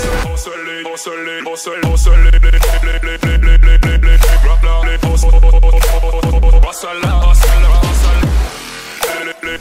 Klaxon TM4 n°1.ogg - Petit Fichier
klaxon-tm4-n-1.ogg